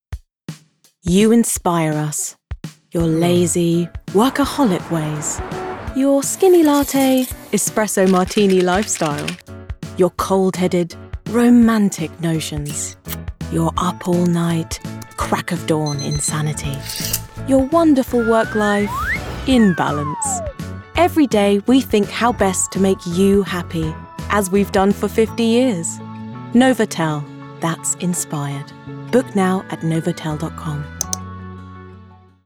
Voice Reel
Novotel - Inspiring, Fun, Engaging